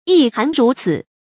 yī hán rú cǐ
一寒如此发音